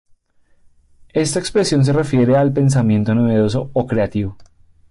cre‧a‧ti‧vo
Pronunciado como (IPA)
/kɾeaˈtibo/